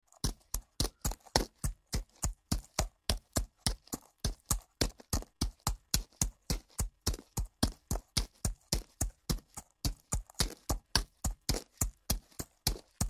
دانلود آهنگ اسب 10 از افکت صوتی انسان و موجودات زنده
جلوه های صوتی
دانلود صدای اسب 10 از ساعد نیوز با لینک مستقیم و کیفیت بالا